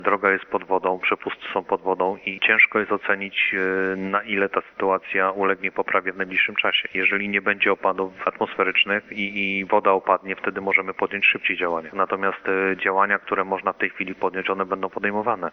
-mówi w rozmowie z Twoim Radiem wójt Starej Dąbrowy Mieczysław Włodarczyk .